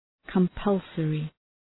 Προφορά
{kəm’pʌlsərı}